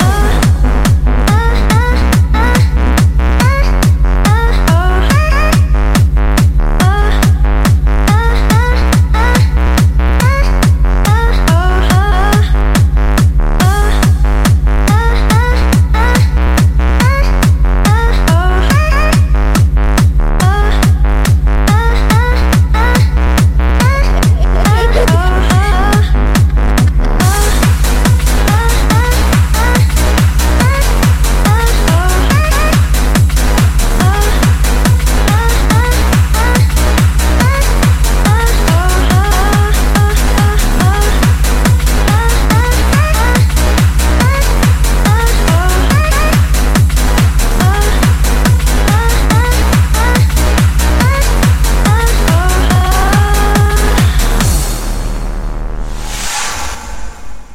• Качество: 141, Stereo
Trans